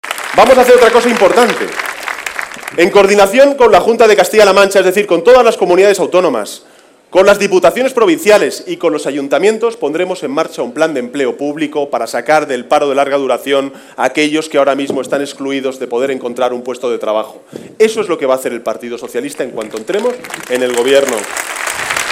En el mitín que ha tenido lugar en Albacete
Cortes de audio de la rueda de prensa